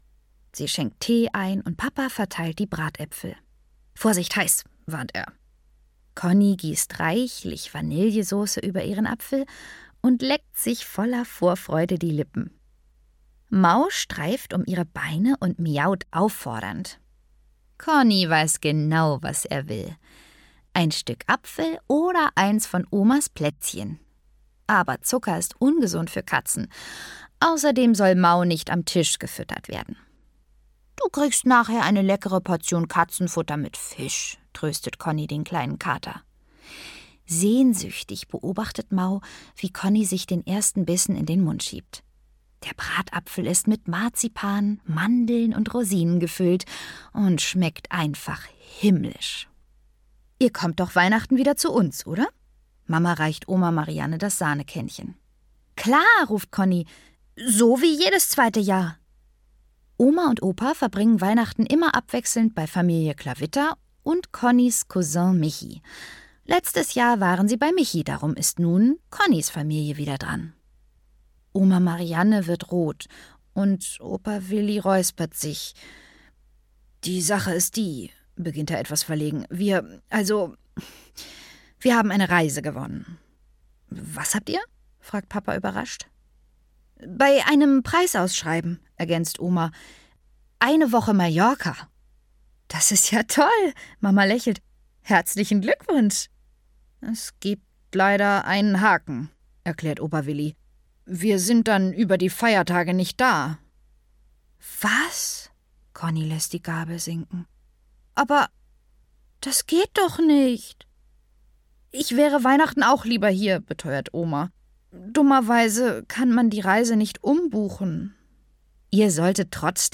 Connis großer Adventskalender (Meine Freundin Conni - ab 6) - Karoline Sander - Hörbuch